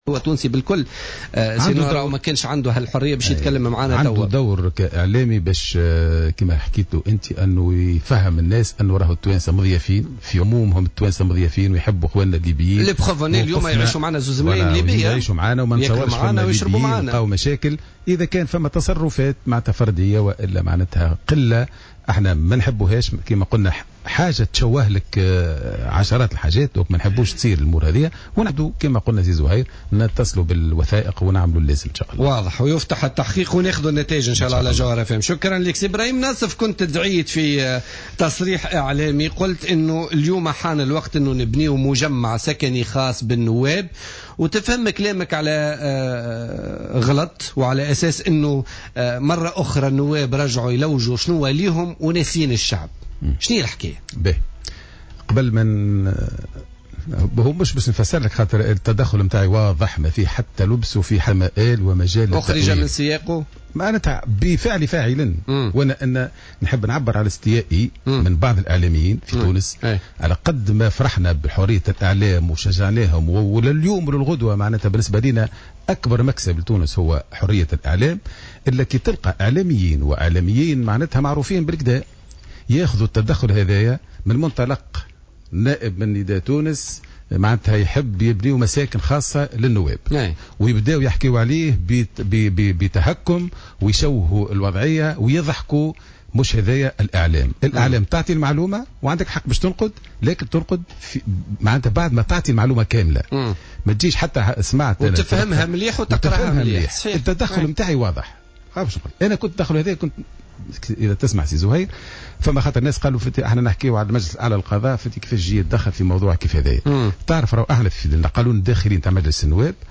قال النائب عن نداء تونس بمجلس نواب الشعب ابراهيم ناصف ضيف بوليتيكا اليوم الخميس 21 ماي 2015 أن مداخلته بشأن تخصيص شقق للنواب قريبة من المجلس أخرجت من سياقها مؤكدا أن الضجة التي أحدثتها تصريحاتها ليس لها مبرر على حد قوله.